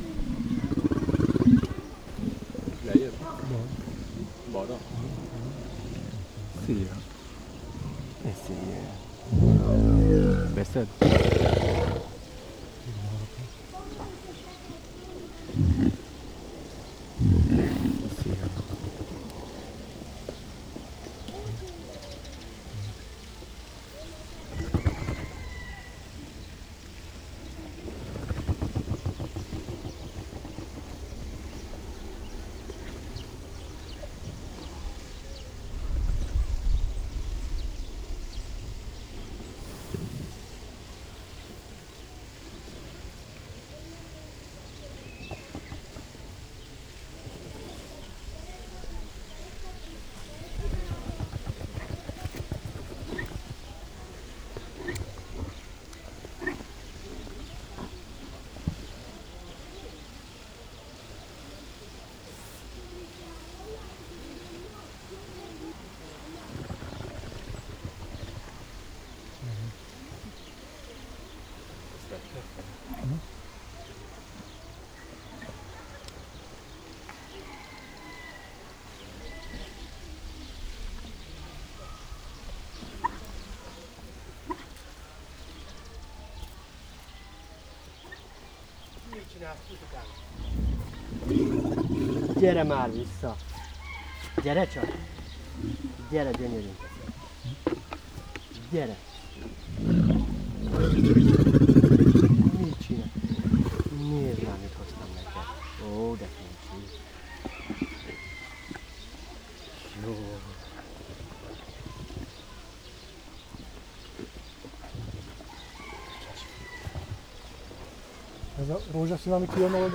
Directory Listing of /_MP3/allathangok/nyiregyhazizoo2010_standardt/egypuputeve/
orratvakargatom_majdjonazallatgondozo03.37.wav